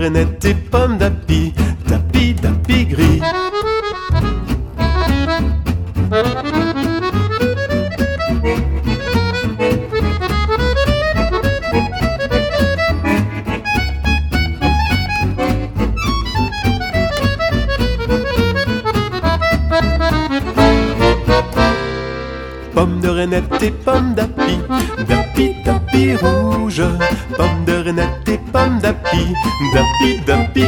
cinq musiciens de jazz manouche.